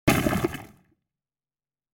جلوه های صوتی
دانلود صدای تانک 10 از ساعد نیوز با لینک مستقیم و کیفیت بالا